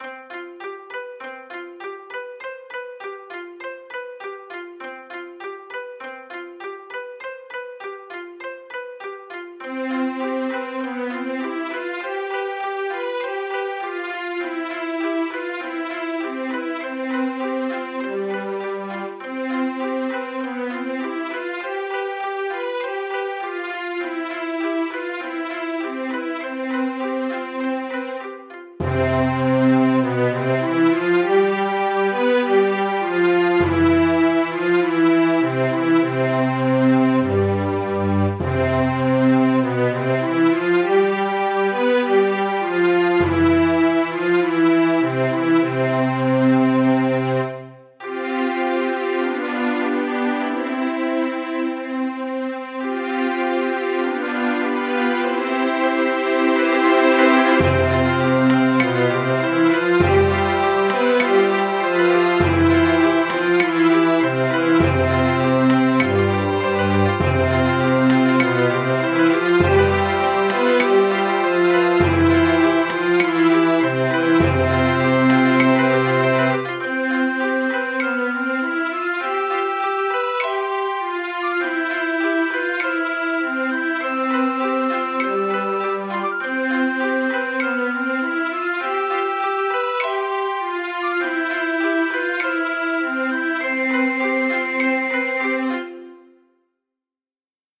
お正月にぴったりな雅なBGM
ゆったり、和風、壮大、季節・行事幻想的